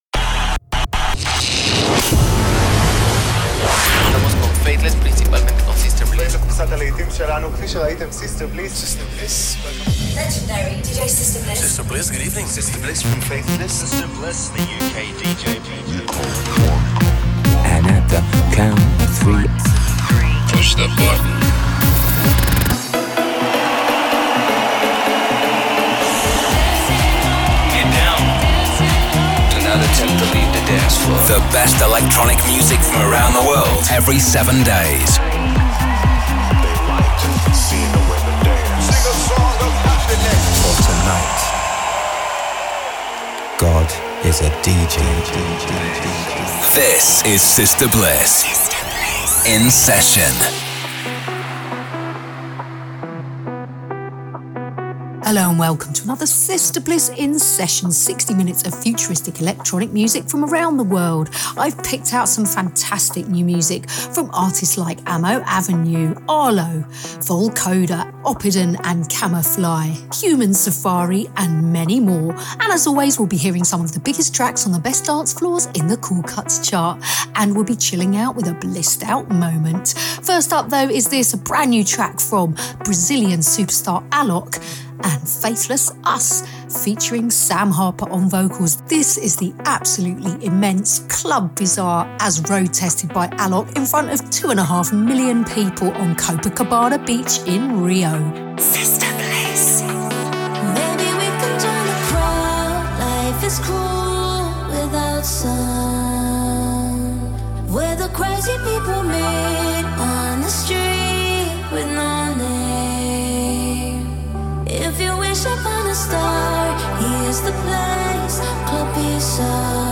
new upfront electronic music
a live DJ mix